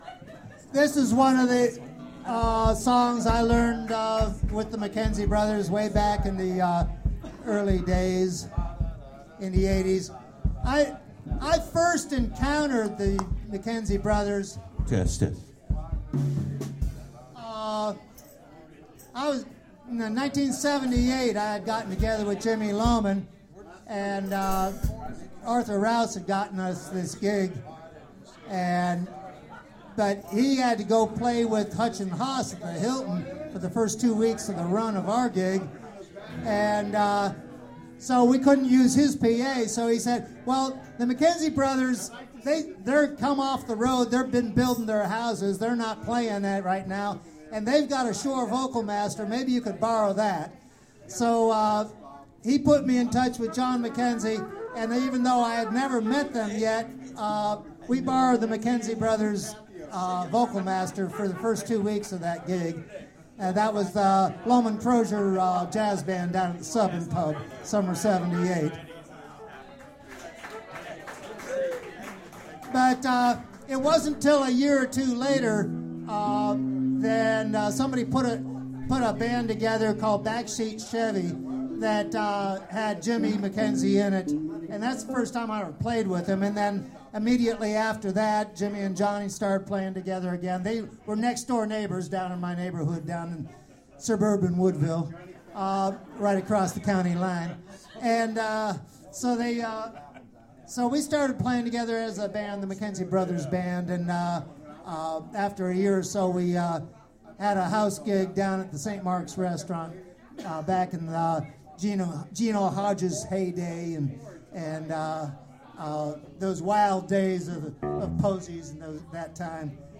- Memorial concert at Bird's -
These links are to the recording of the show from my Tascam DR-07 that was mounted right in front of the monitor.